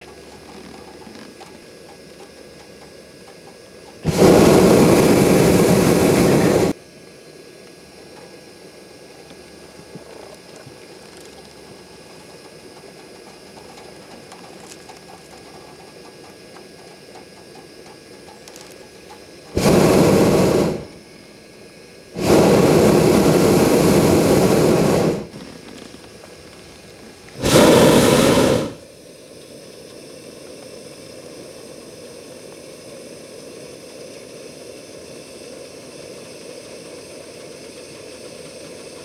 Captive Balloon Sound
transport